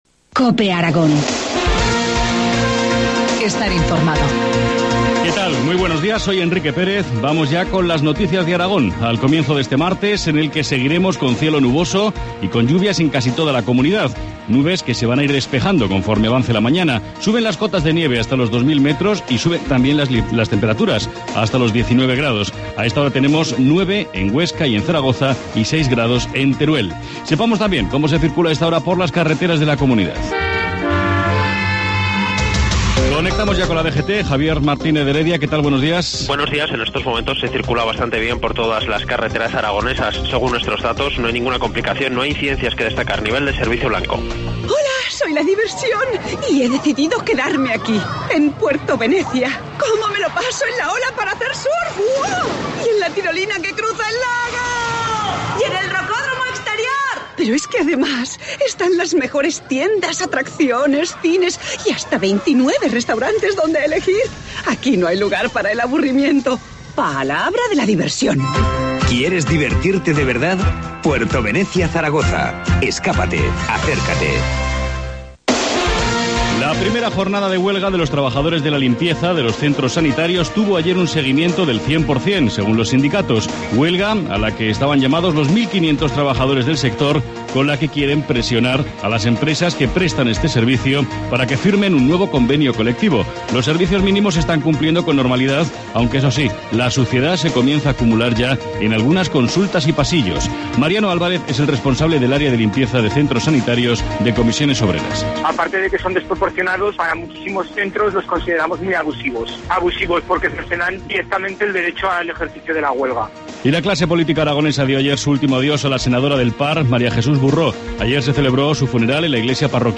Informativo matinal, martes 21 de mayo, 7.25 horas